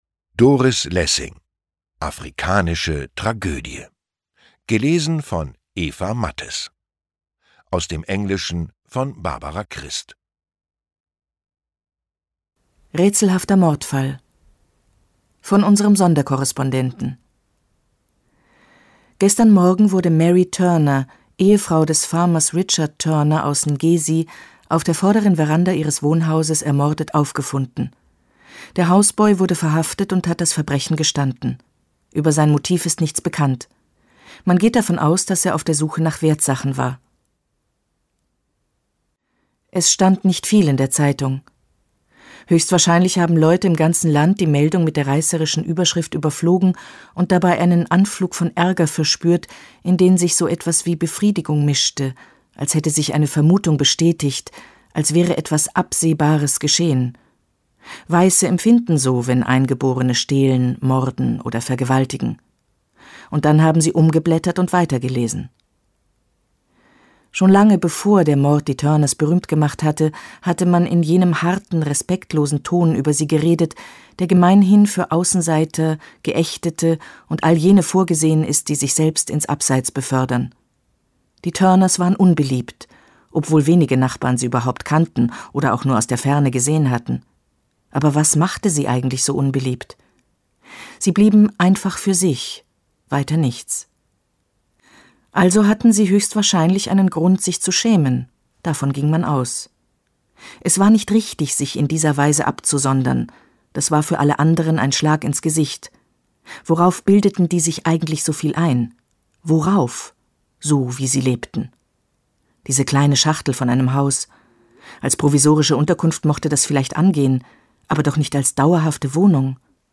Lesung mit Eva Mattes (1 mp3-CD)
Eva Mattes (Sprecher)